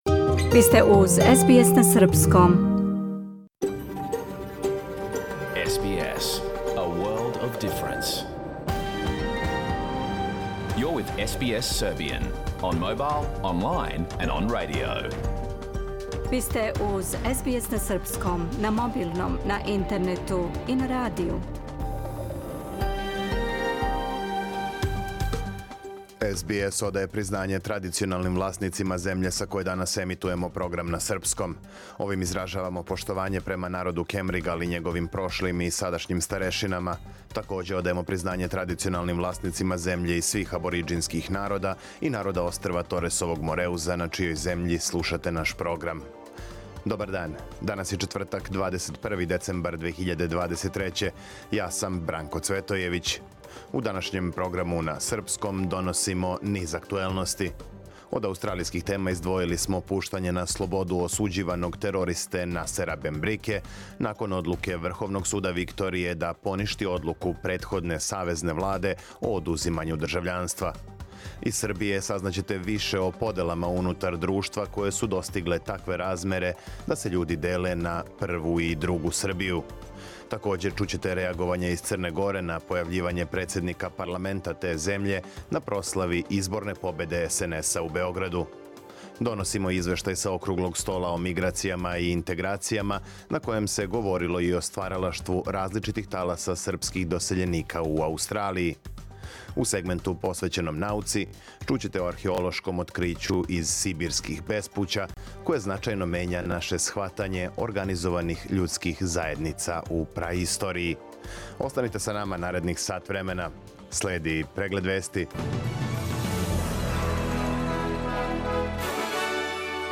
Програм емитован уживо 21. децембра 2023. године
Уколико сте пропустили данашњу емисију, можете је послушати у целини као подкаст, без реклама.